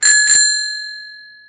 Cateye PB-300 Hit-Bell csengő, ezüst
question_markTermékkör Ding-Dong csengő
Erős pengető mechanikájú, alumínium felső résszel.
cateye_hit.mp3